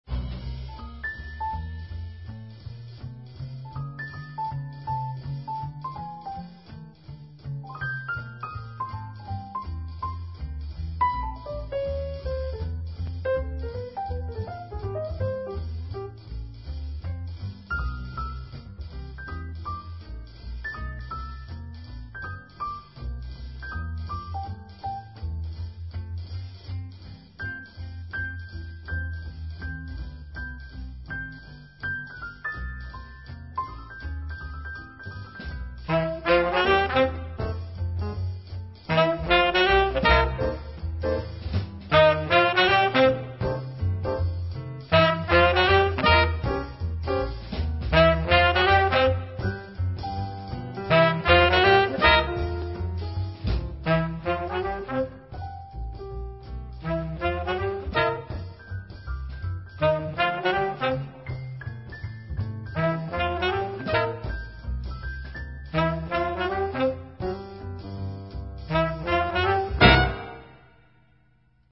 Alcuni brani registrati dal vivo.